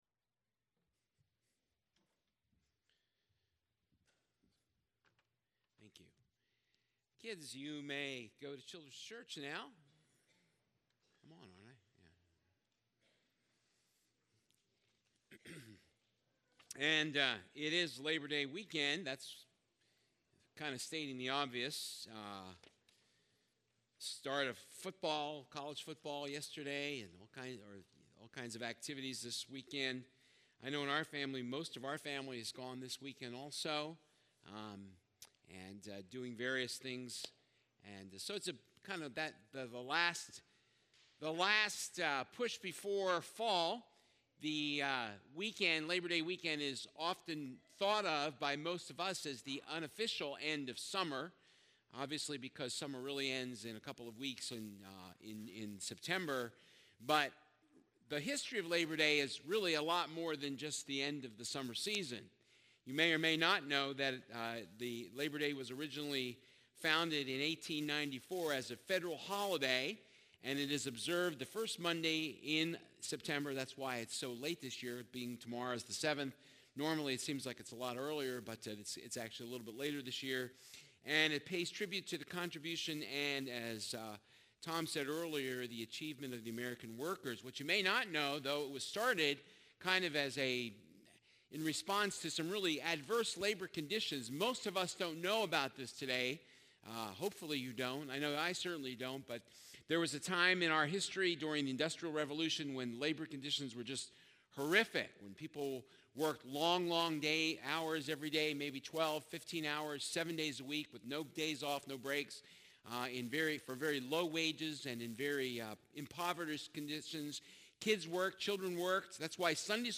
1 Corinthians 15:58 Service Type: Sunday Service God's Green Hats